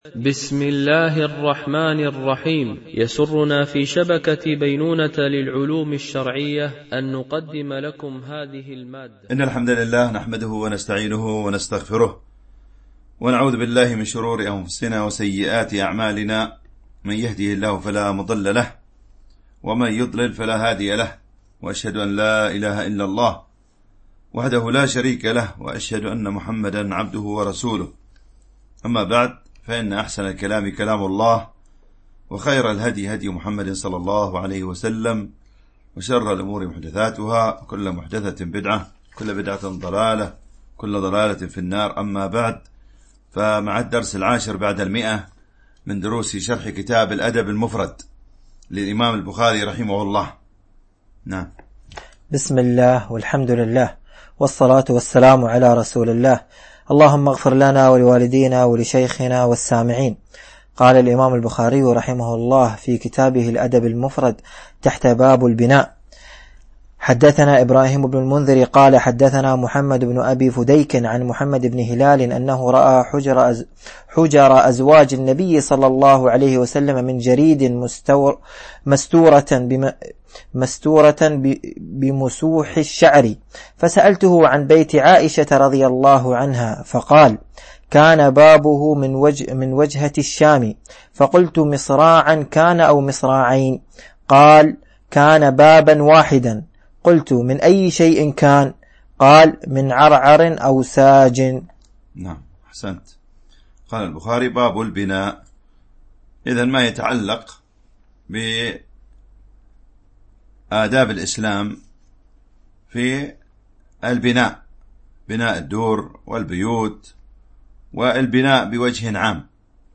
شرح الأدب المفرد للبخاري ـ الدرس 110 ( الحديث 776 – 783 )